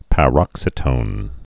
(pă-rŏksĭ-tōn)